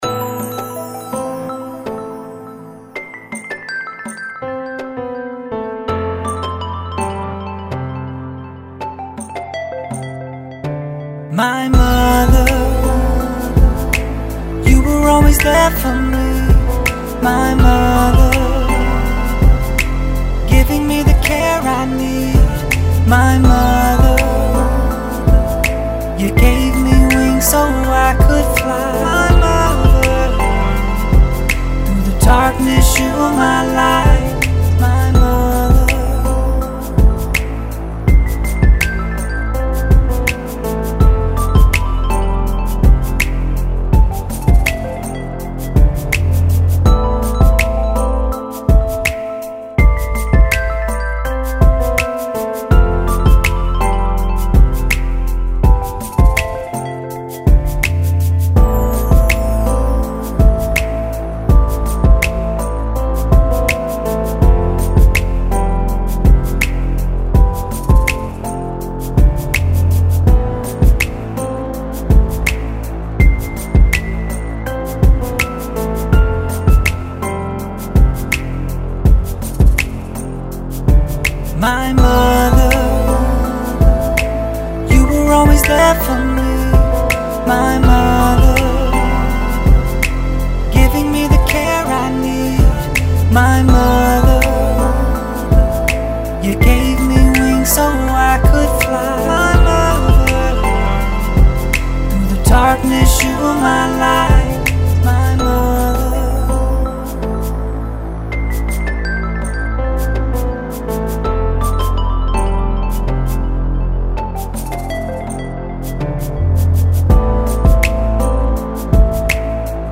82 BPM.
Beats With Hooks